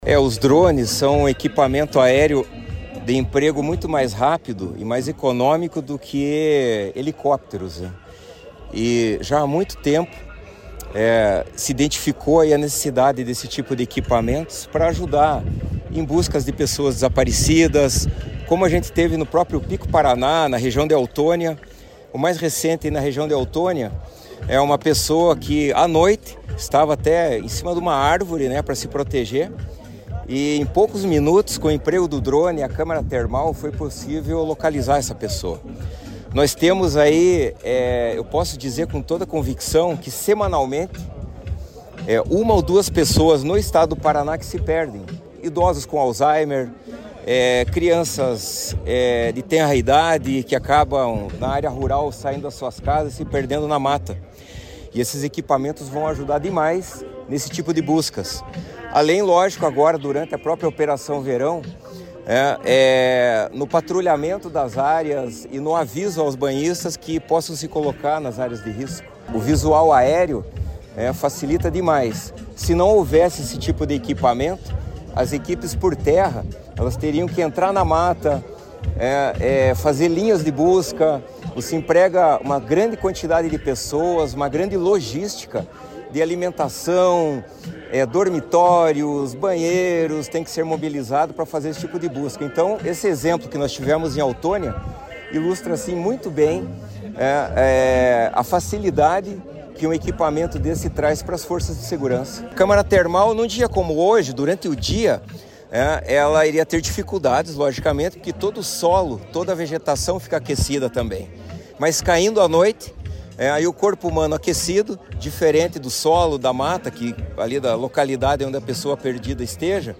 Sonora do comandante-geral do CBMPR, coronel Antônio Carlos Hiller, sobre a entrega de 243 drones para monitoramento aéreo das forças de segurança do Paraná